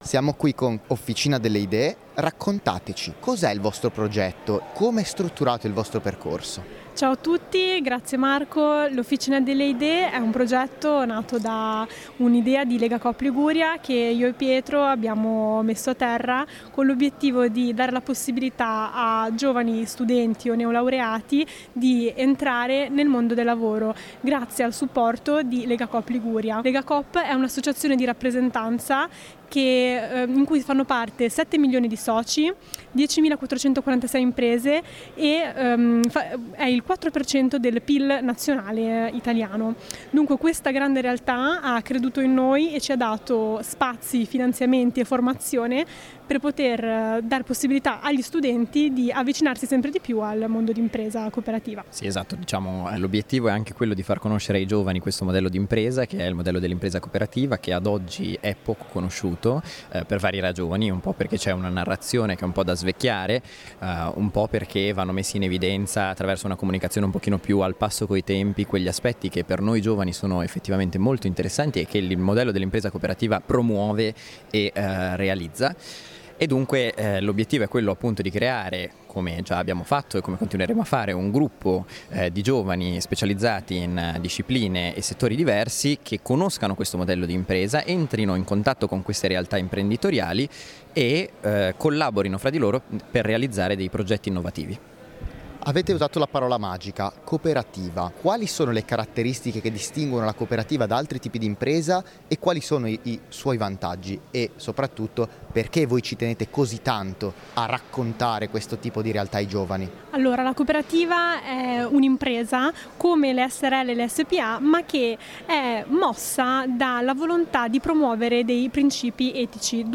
Intervista di